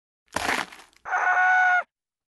Sound Buttons: Sound Buttons View : Bones Scream Funny